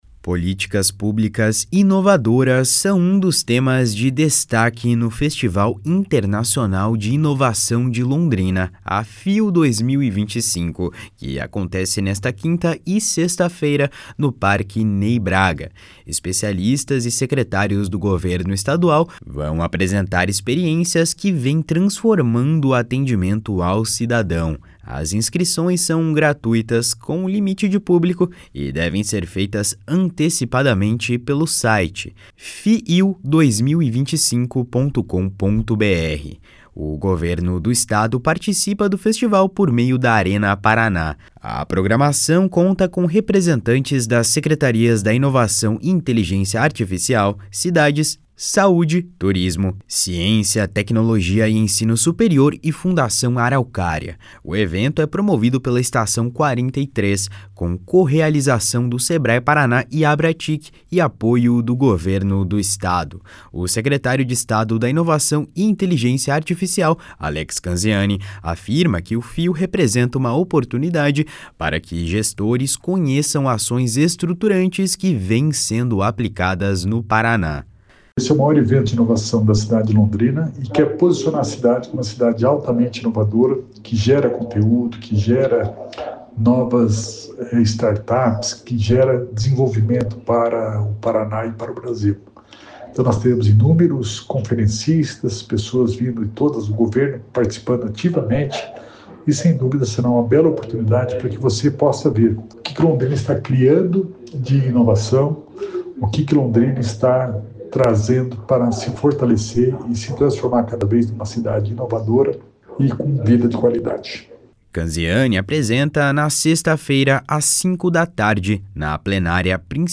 // SONORA ALEX CANZIANI //